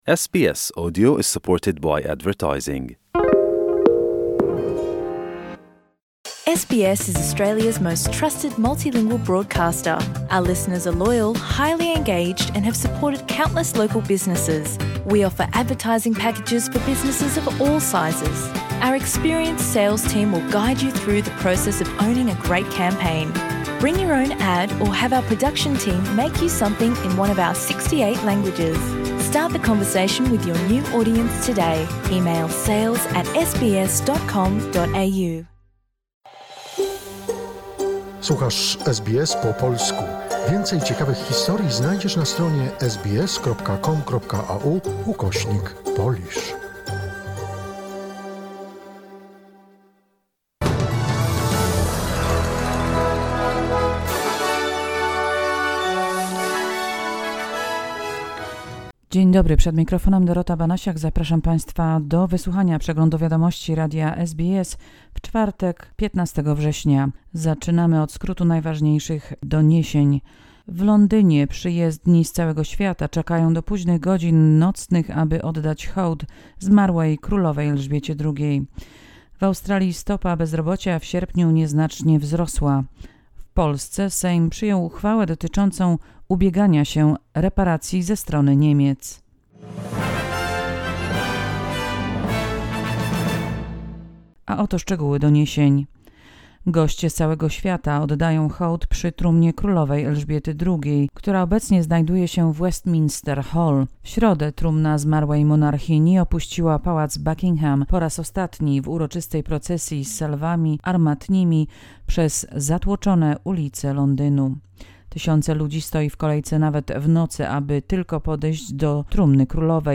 SBS Flash News in Polish, 15 September 20222